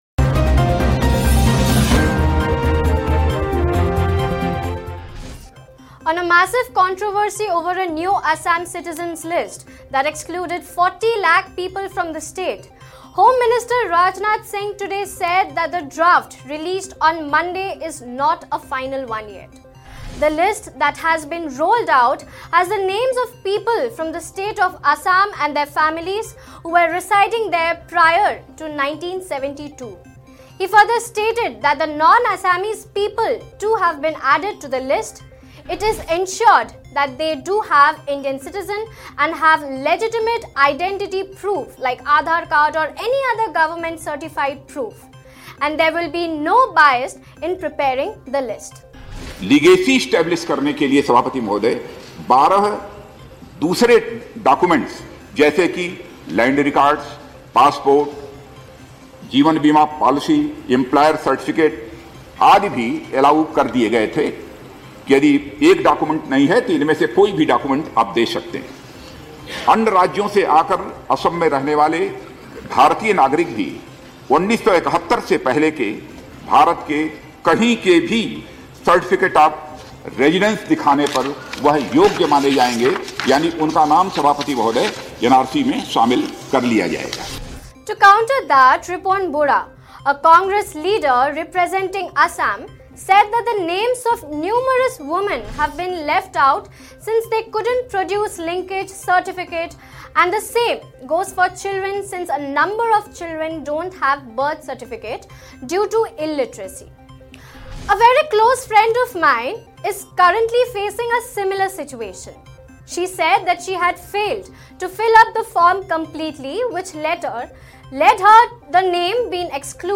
(rajnath byte) All genuine Indian citizens will be added to the list.
News Report / Is the Government really prepared for the NRC draft?